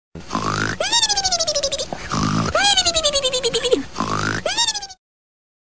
Snore Mimimmimmimim